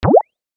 Descarga de Sonidos mp3 Gratis: bip 7.
descargar sonido mp3 bip 7